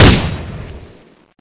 Index of /server/sound/weapons/tfa_cso/m249ex
fire.wav